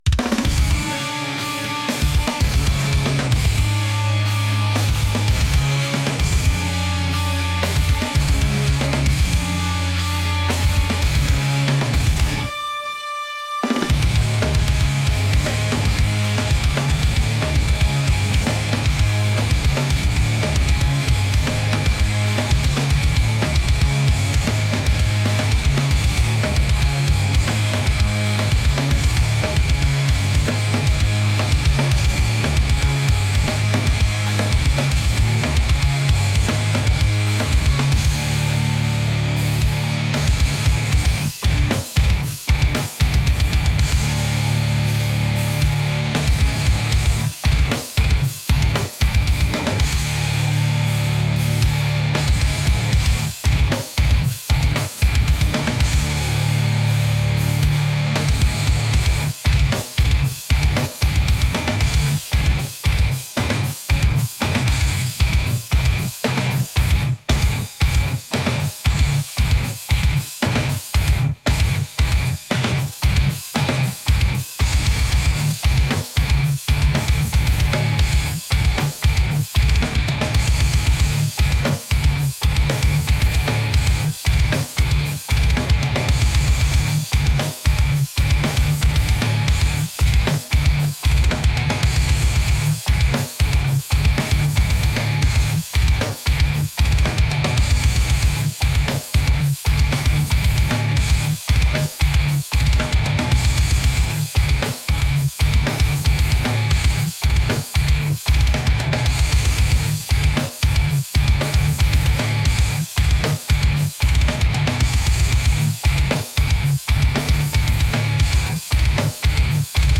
aggressive